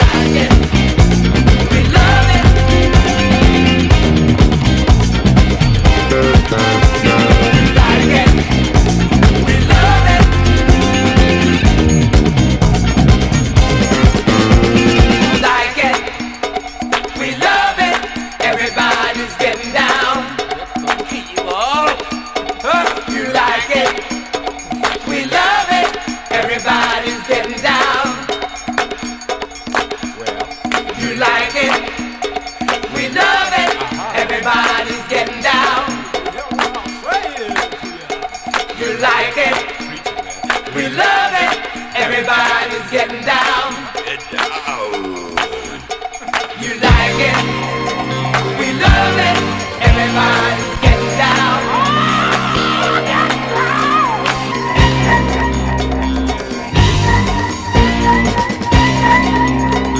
JAPANESE DISCO / DISCO BOOGIE
カルト和モノ・ディスコ・ブギー12インチ！
キラキラしたギター・カッティングやパーカッシヴなアレンジも効いた